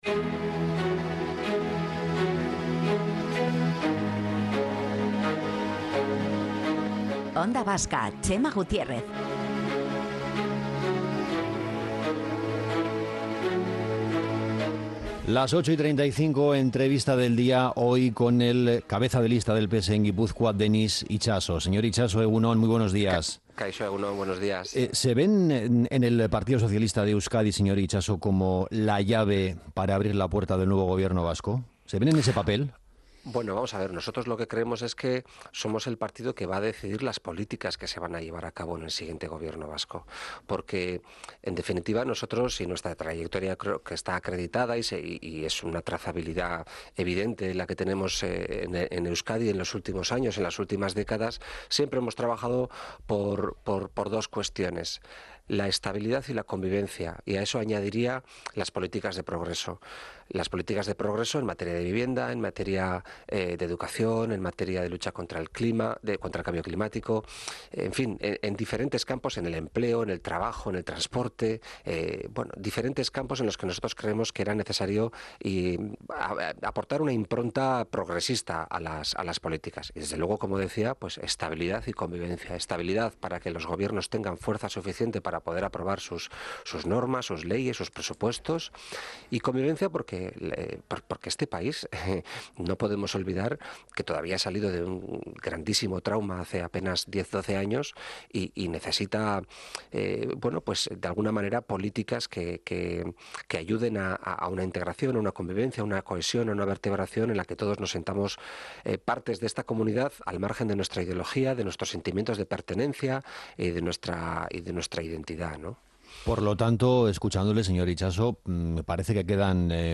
Pero es el momento de que cada uno ofrezca sus recetas y proyectos", subraya entrevistado en ONDA VASCA.